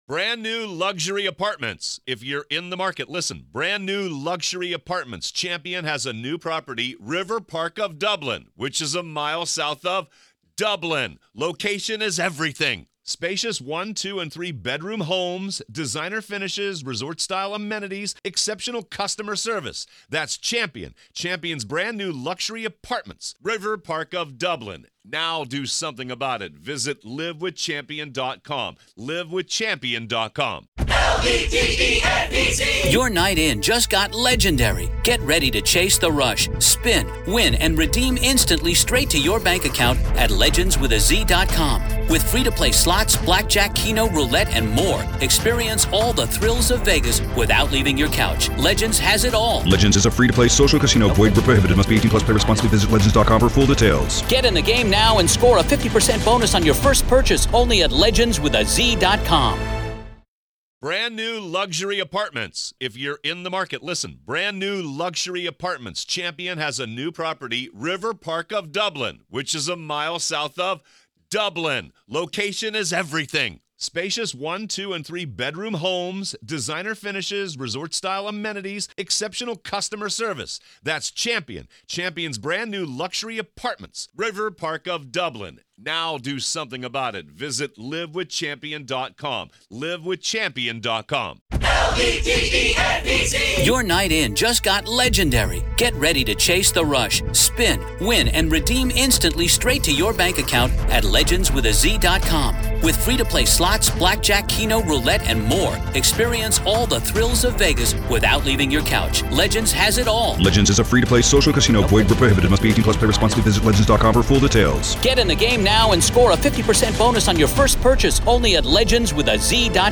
IDAHO VS. KOHBERGER HEARING-RAW COURT AUDIO PART 3